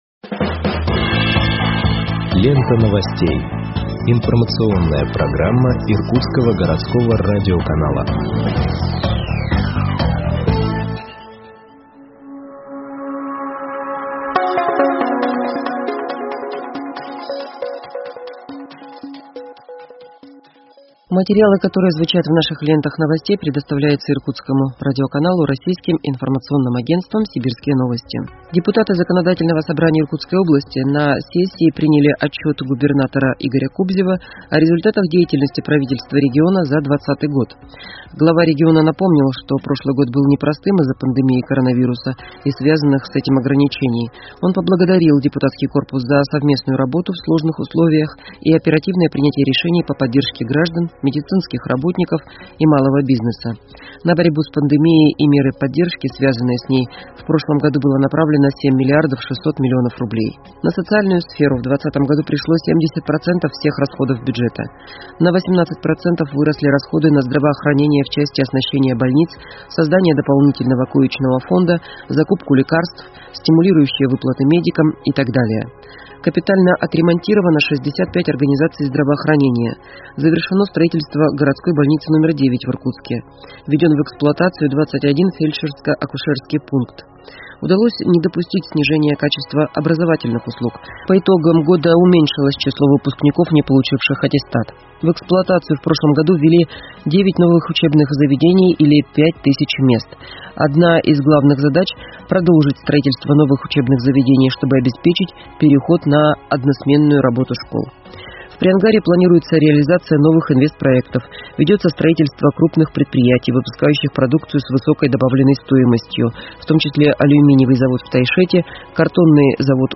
Выпуск новостей в подкастах газеты Иркутск от 24.09.2021 № 1